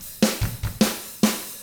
146ROCK F1-L.wav